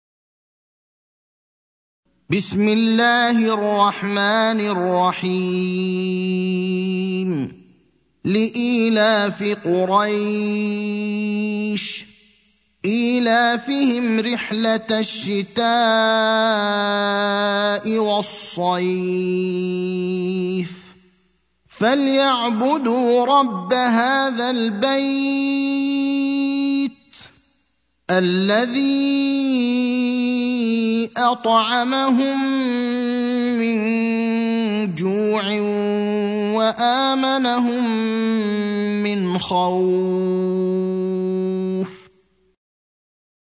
المكان: المسجد النبوي الشيخ: فضيلة الشيخ إبراهيم الأخضر فضيلة الشيخ إبراهيم الأخضر قريش (106)